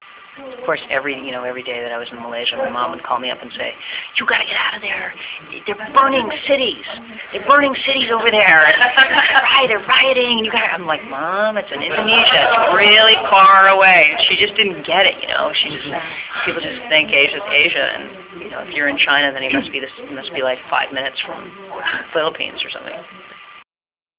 Interview Highlights (Audio)